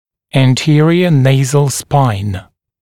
[æn’tɪərɪə ‘neɪzl spaɪn][эн’тиэриэ ‘нэйзл спайн]передняя носовая ось (ANS), передняя носовая ость